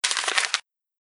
• Качество: высокое
Звук опустошения корзины в Windows 98